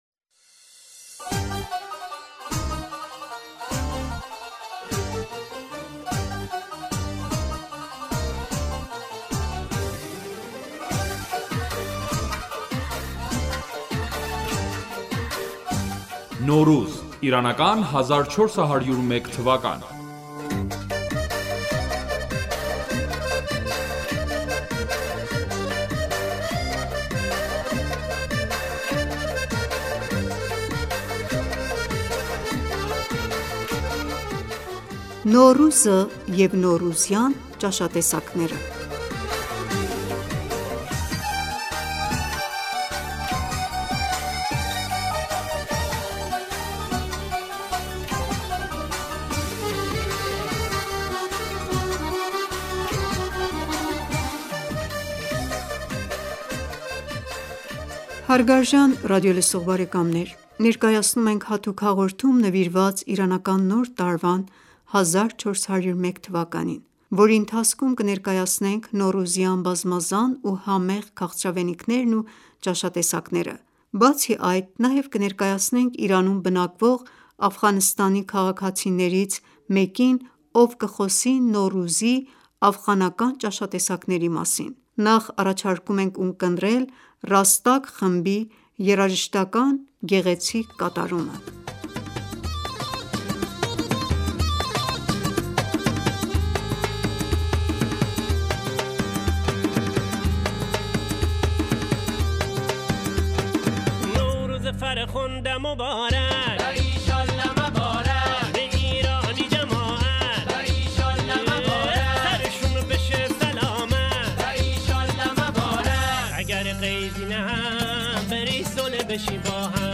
Դուք լսում էիք Իրանի հարավային շրջանի գեղեցիկ և հոգեզմայլ երաժշտությունը։ Ոգեշնչված այս տարածաշրջանի տաք ու ջերմ բնությունից՝ հարավային Իրանի երաժշտությունը լի է ջերմությամբ, էներգիայով և խանդավառությամբ: